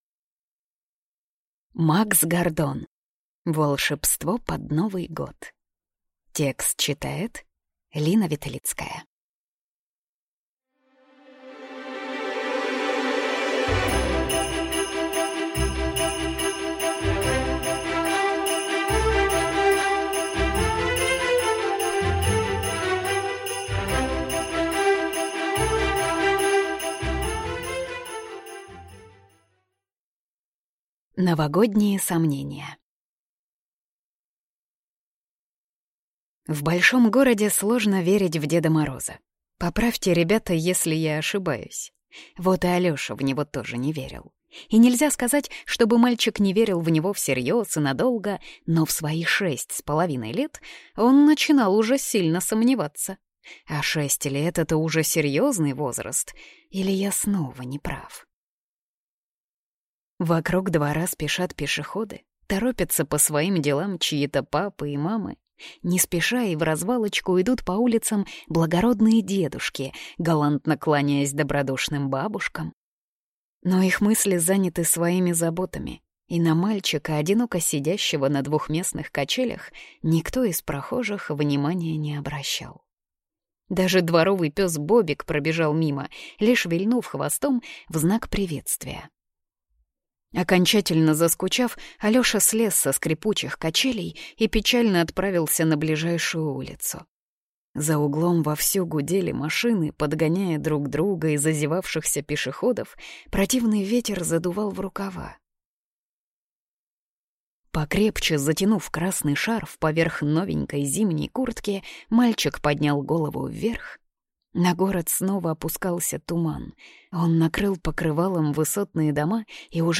Аудиокнига Волшебство под Новый Год | Библиотека аудиокниг